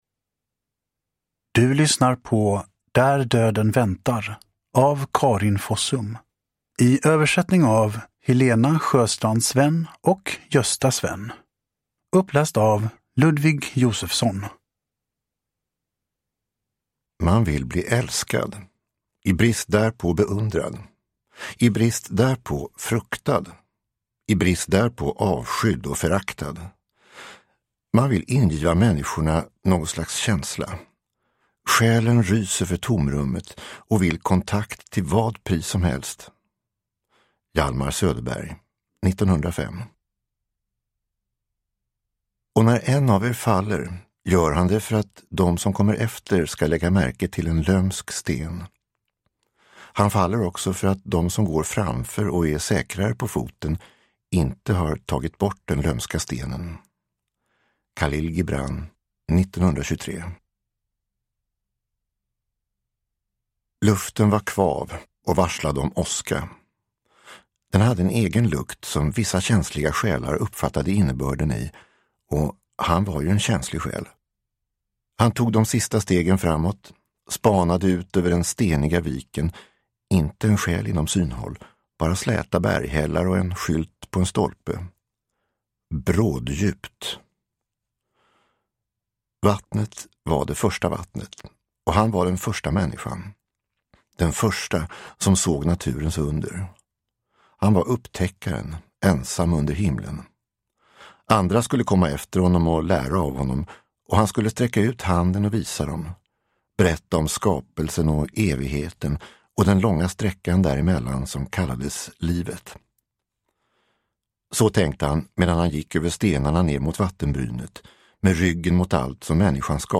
Där döden väntar – Ljudbok – Laddas ner